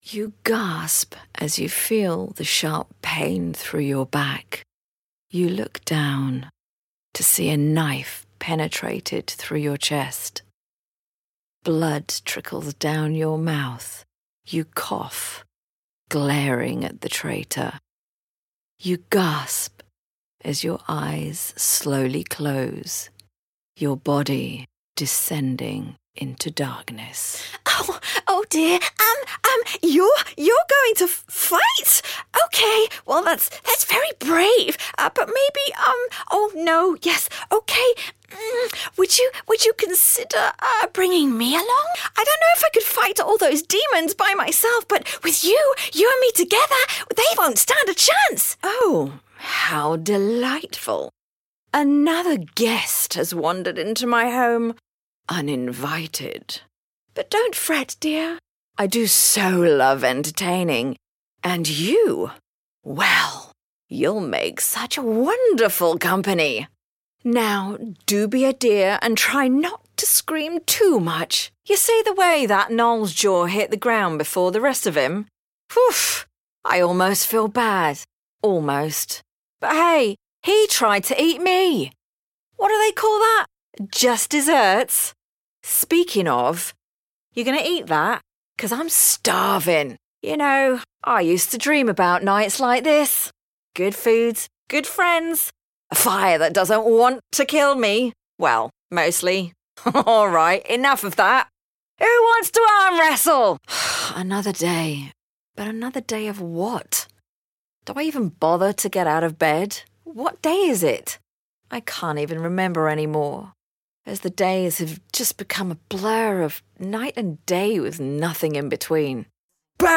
Female
Adult (30-50)
Eloquent and articulate, every single word is enunciated clearly.
Character / Cartoon
Narrator, Character, Games
All our voice actors have professional broadcast quality recording studios.